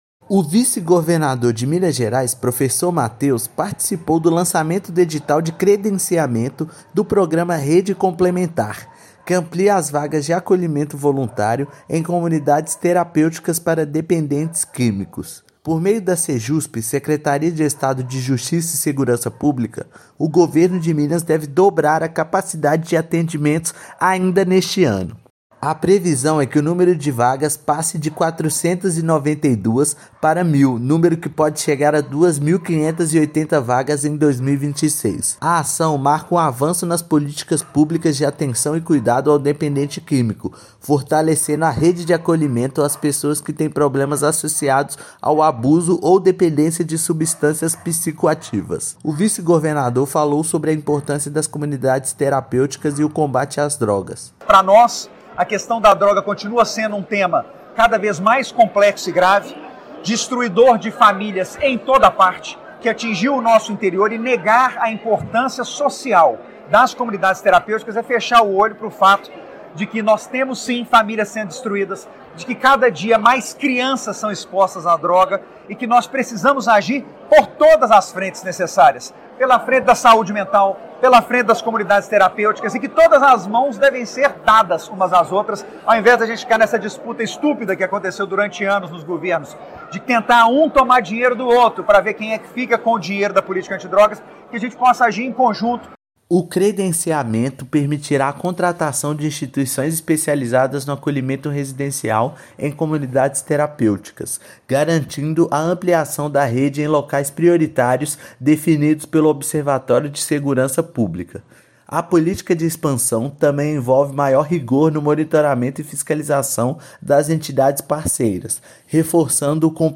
Estado investe em acolhimento humanizado e amplia políticas públicas com expansão estratégica e credenciamento de novas entidades; previsão é aumentar em 424% o número de vagas até 2026. Ouça matéria de rádio.
Rádio_matéria_vice-governador_comunidades_terapeuticas.mp3